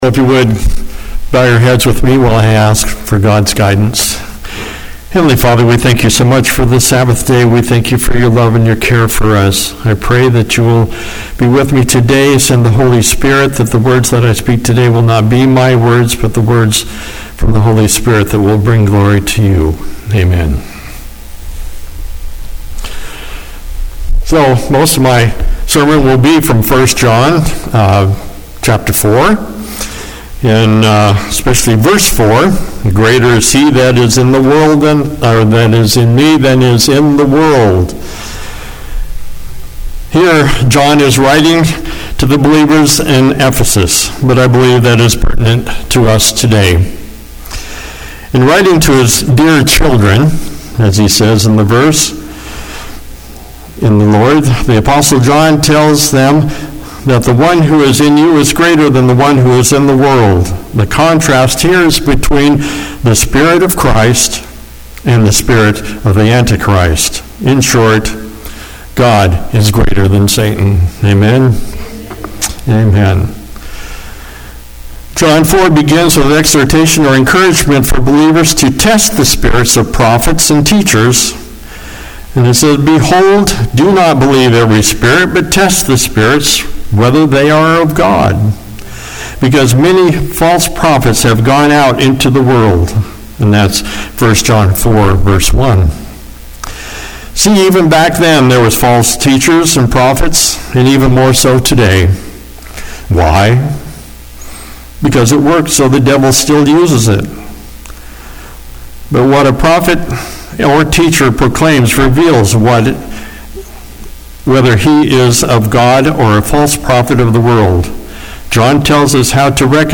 Seventh-day Adventist Church, Sutherlin Oregon
Sermons and Talks